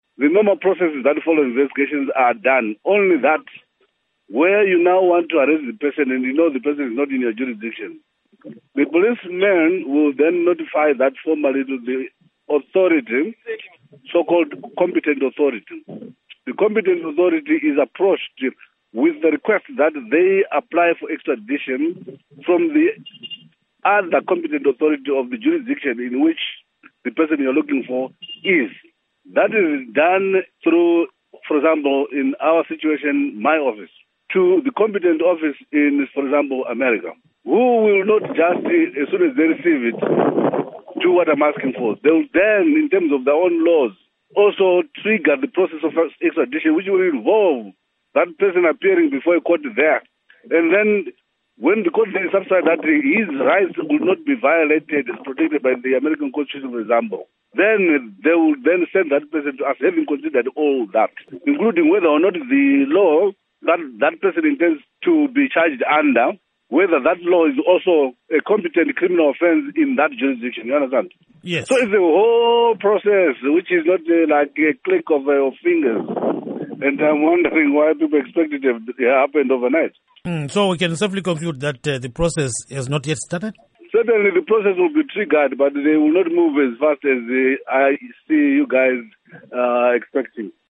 Interview Johannes Tomana on Cecil The Lion